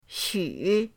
xu3.mp3